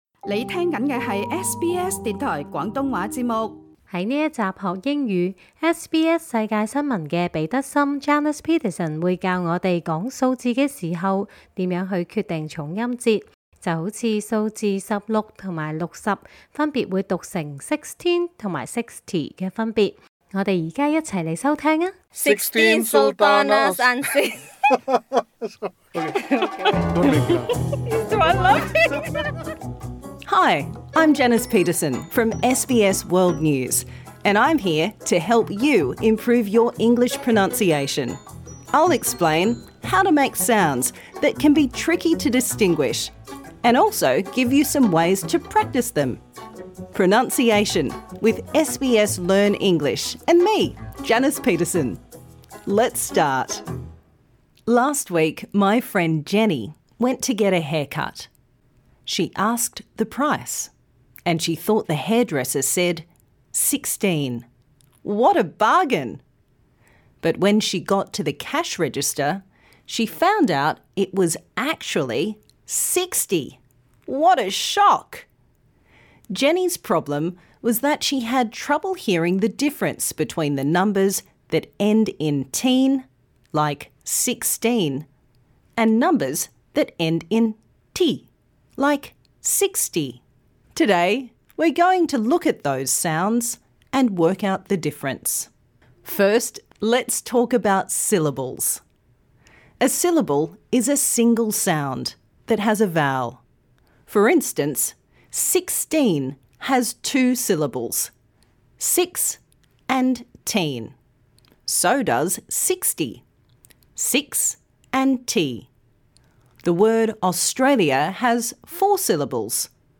Text for Practise : I went to the store and bought thirteen tomatoes for thirty dollars, fourteen fish for forty dollars, fifteen forks for fifty dollars, etc. Minimal Pairs : ‘teen’ is the stressed syllable - it is long and clear and the /t/ is clearly pronounced: thirteen, fourteen... ‘ty’ is the unstressed syllable- it is short and quick and the ‘t’ is pronounced /d/: thirty, forty, fifty...